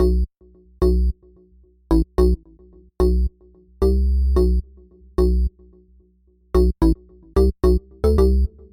松脆的合成器
描述：虚拟仪器。NI Massive
Tag: 110 bpm Electro Loops Synth Loops 1.48 MB wav Key : C Cubase